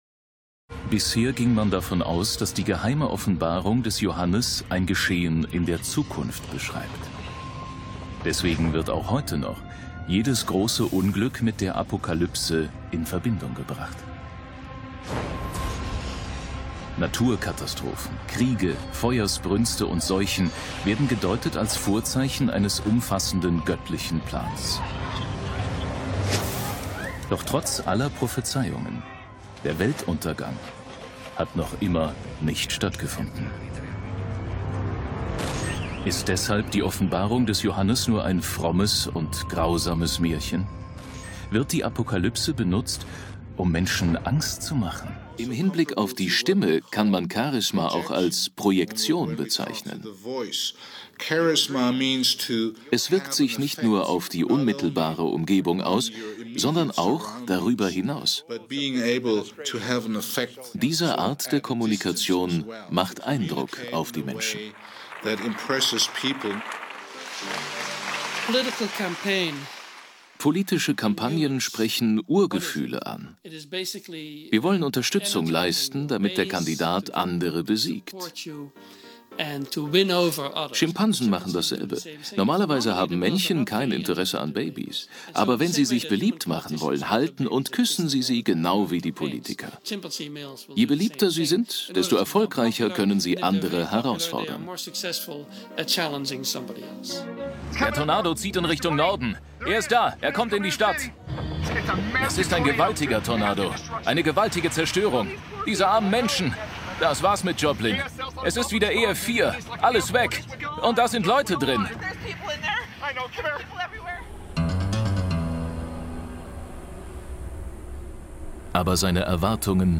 Sprechprobe: Sonstiges (Muttersprache):
German voice artist for all kinds of commercials, audiobooks, corporate films, e.g. Gelo Myrtol, Ferrero, Porsche Garmin, Henkel, Universal Pictures, Hyundai amm.